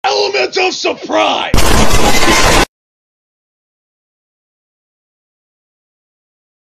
Surprise Sound Effects MP3 Download Free - Quick Sounds